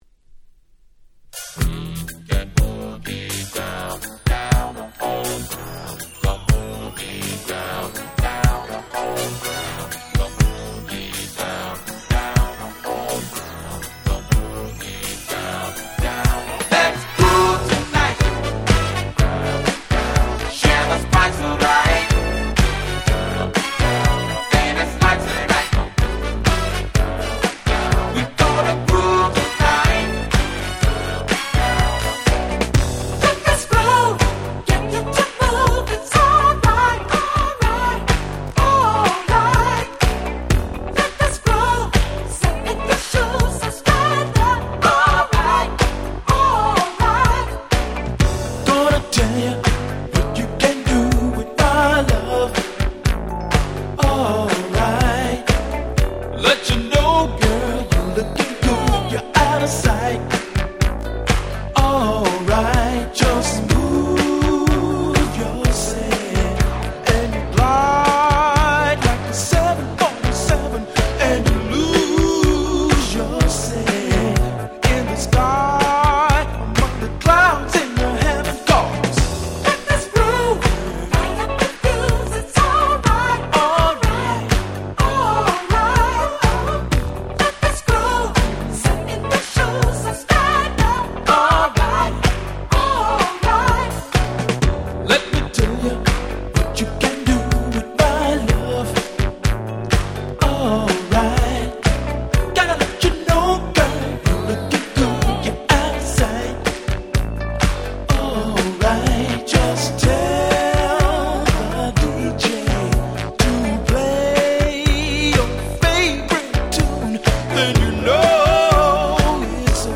81' Monster Hit Disco !!